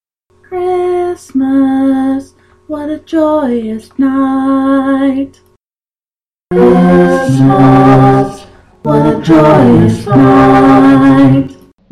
I was having the same issues as you, but I think I’ve found a fairly successful way to turn one person (i.e. me) into what sounds like a choir. Mine is a six-person choir, but you could always go for less or more.
My example chord is F which consists of F, A, and C)
It’s not as perfect as an actual choir, but it can get the job accomplished.
Though unfortunately quite distorted.